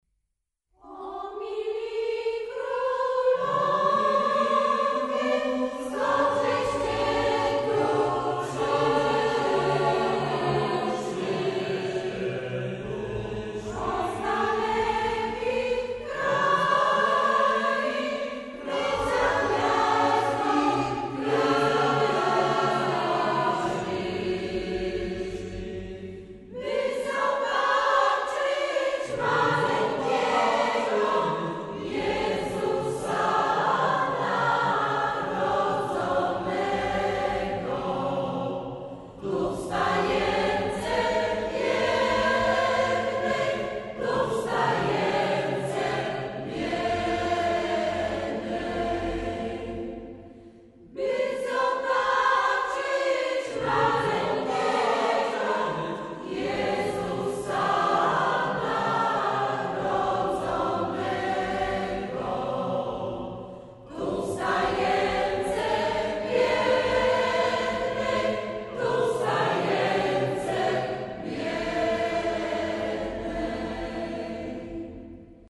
Kolędy - do pobrania - pliki mp3 - fragment utworów z II płyty